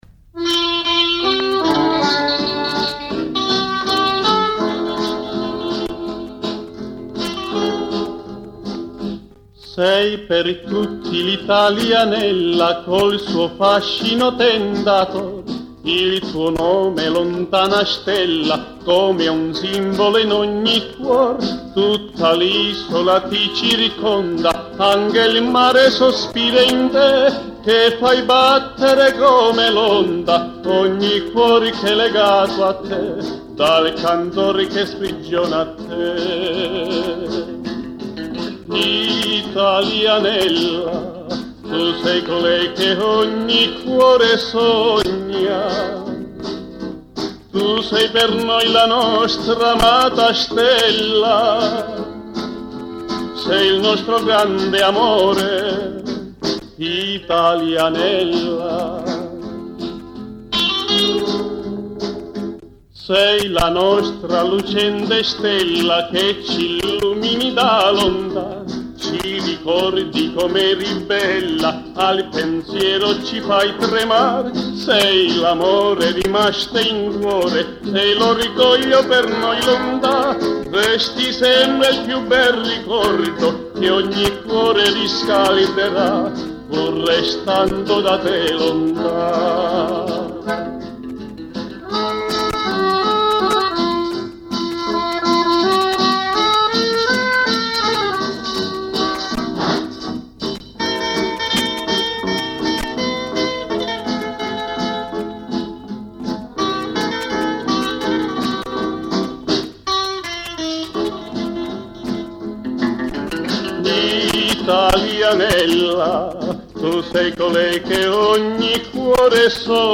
ASCOLTA ALCUNI BRANI CANTATI